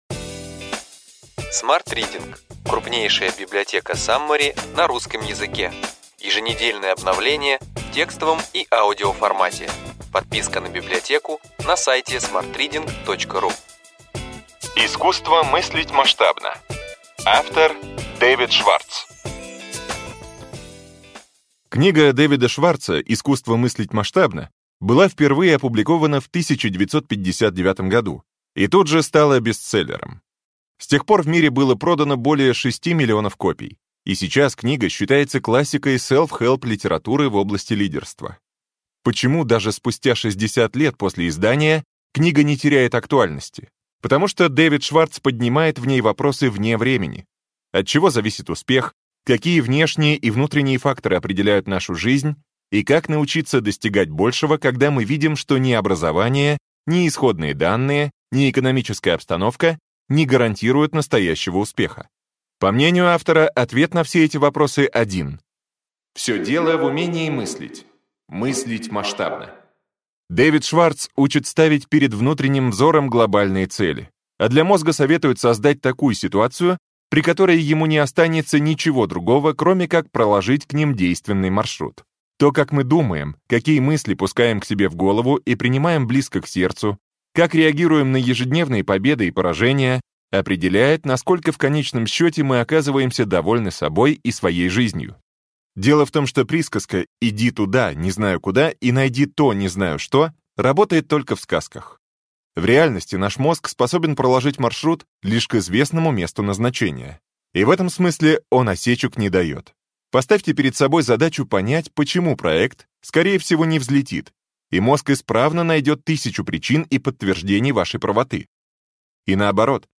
Аудиокнига Ключевые идеи книги: Искусство мыслить масштабно.